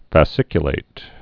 (fə-sĭkyə-lĭt) also fas·cic·u·lat·ed (-lātĭd)